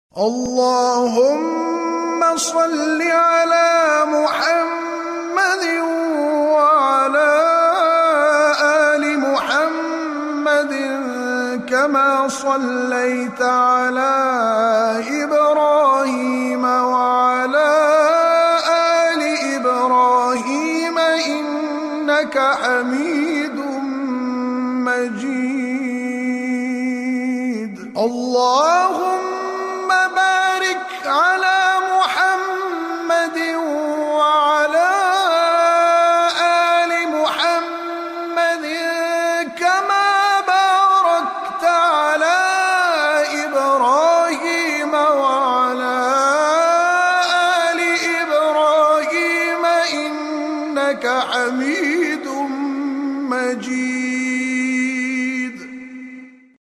Audio Category:Zikr Hits:41976 Date: | File Size:1MB Duration:1min Time:After Isha Prayer Venue: Home